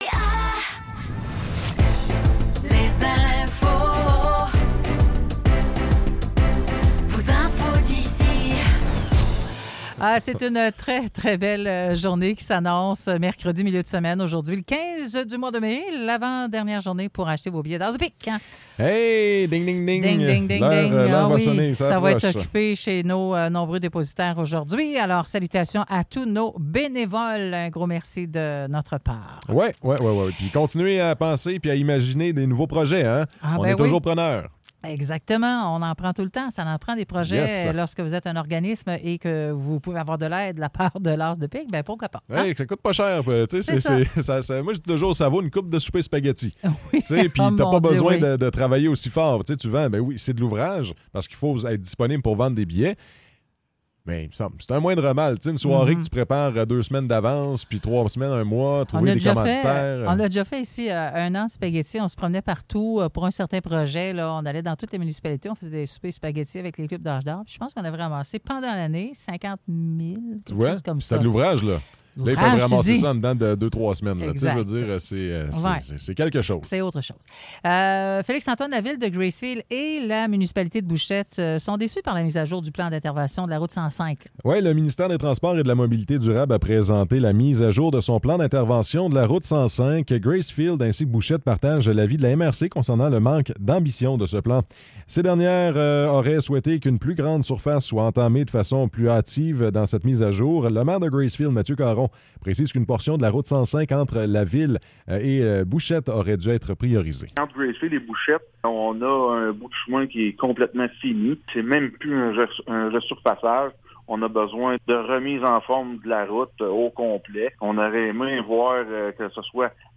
Nouvelles locales - 15 mai 2024 - 9 h